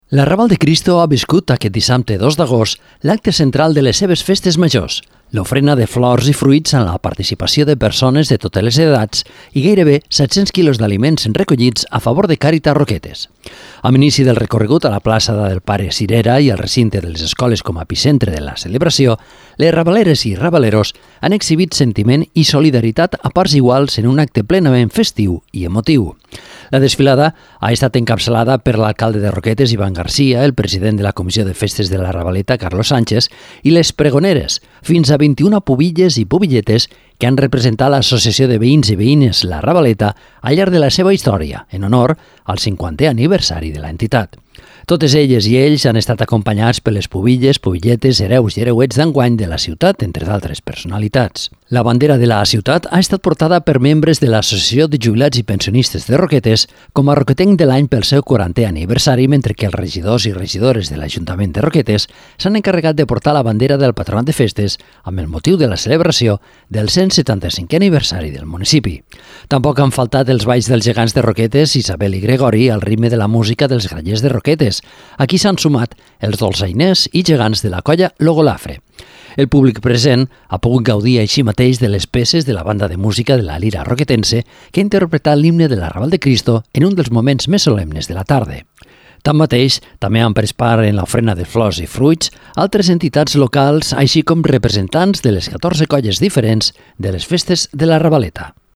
Tampoc han faltat els balls dels Gegants de Roquetes Isabel i Gregori, al ritme de la música dels Grallers de Roquetes, a qui s’han sumat els dolçainers i gegants de la colla Lo Golafre. El públic present ha pogut gaudir així mateix de les peces de la banda de música de la Lira Roquetense, que ha interpretat l’himne de la Raval de Cristo, en un dels moments més solemnes de la tarda.